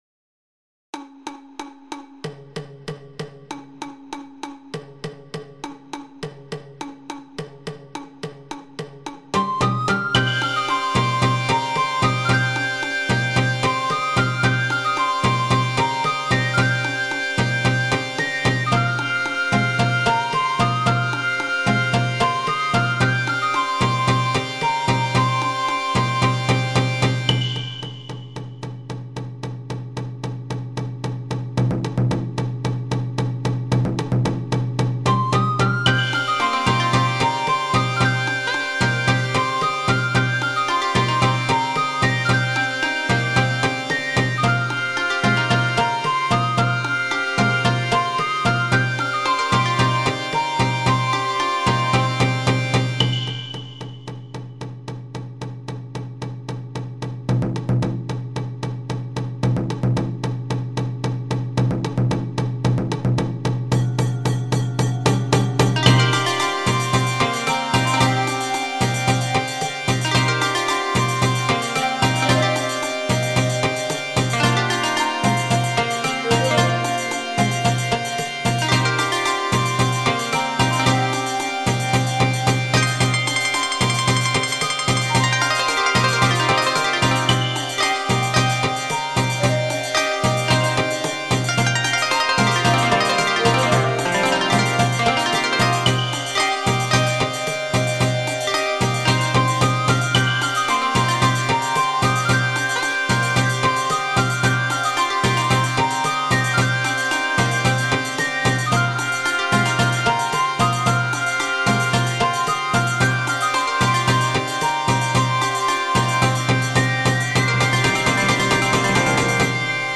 アップテンポロング明るい民族